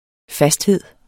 Udtale [ ˈfasdˌheðˀ ]